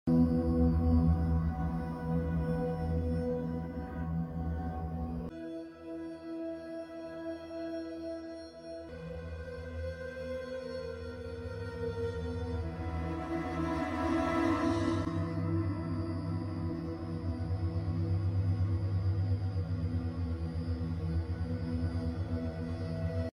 ?what If That ASMR Trigger Sound Effects Free Download